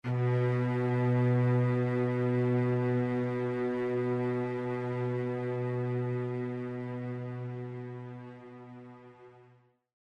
Нота: Си малой октавы (B3) – 246.94 Гц
Note1_B3.mp3